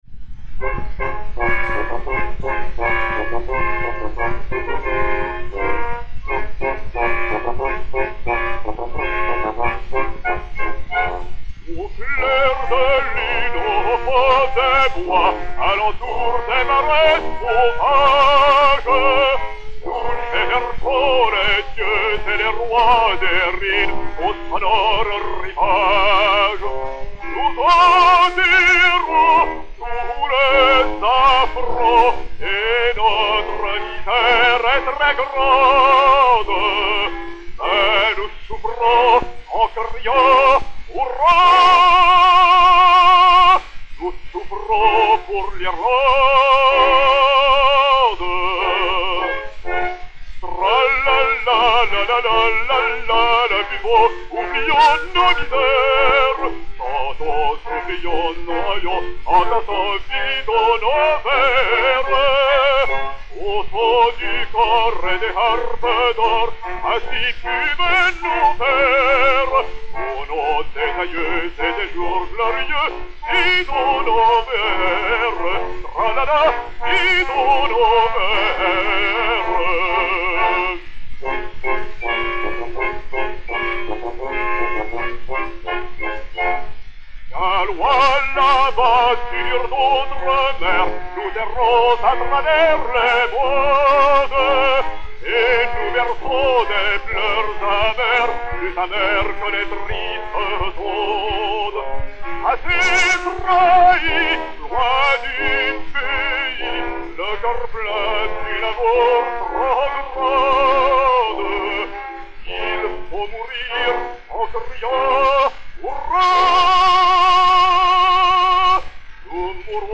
Homophone 8841, mat. 2417B1, enr à Paris v. 1906